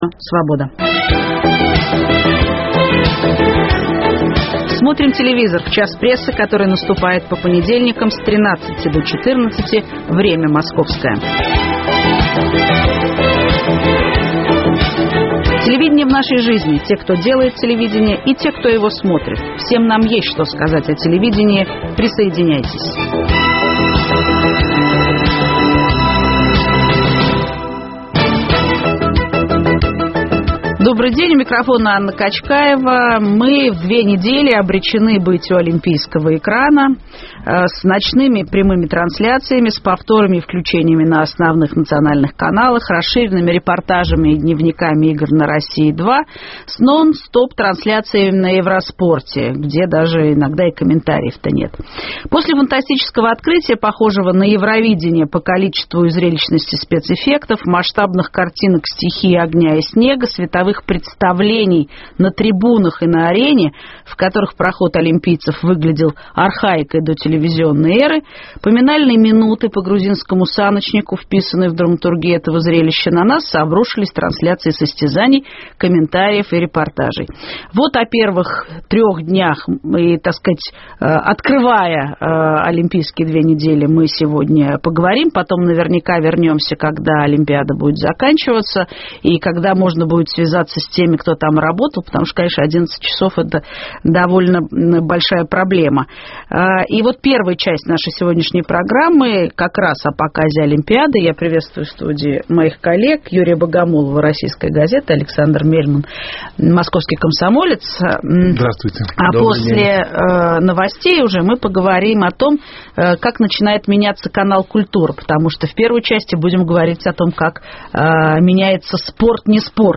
Олимпиада в фокусе: как показывают соревнования национальные и специализированные каналы? Новое лицо "Культуры": как меняется традиционный канал? В студии